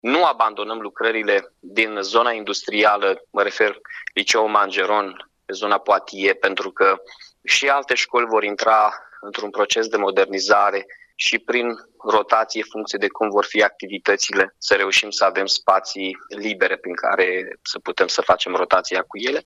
Primarul Mihai Chirica a anunțat că, în paralel vor continua și lucrările de modernizare a Liceului de mecatronică deoarece unitatea școlară are încheiat un parteneriat cu Universitatea Tehnică „Gheorghe Asachi” din Iași.